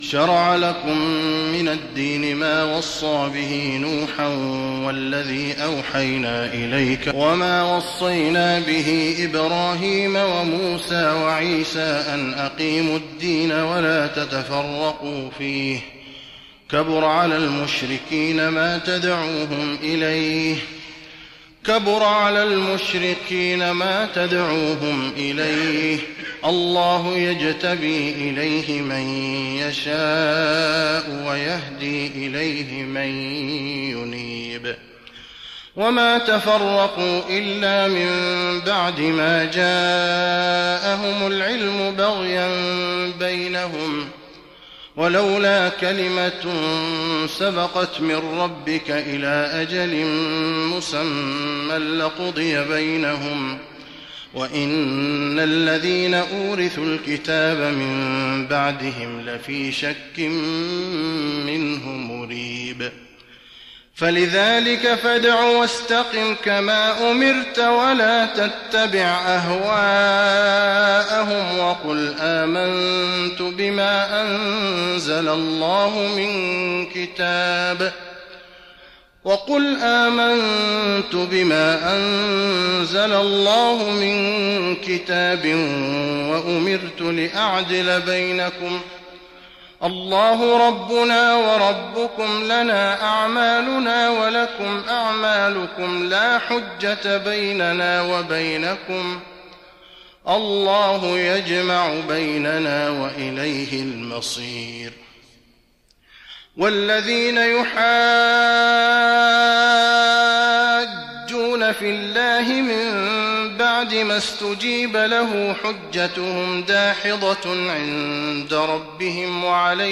تراويح رمضان 1415هـ من سورة الشورى (13-53) الى سورة الزخرف كاملة Taraweeh Ramadan 1415H from Surah Ash-Shura and Az-Zukhruf > تراويح الحرم النبوي عام 1415 🕌 > التراويح - تلاوات الحرمين